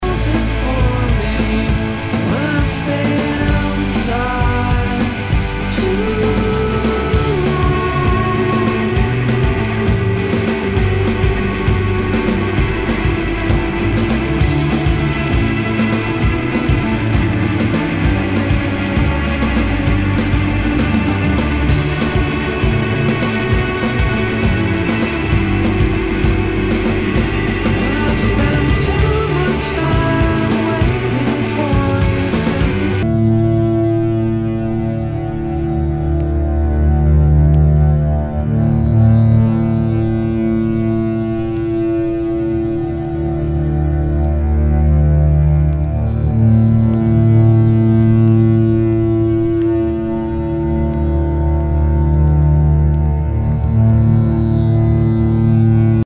ROCK/POPS/INDIE
ナイス！インディー・ロック！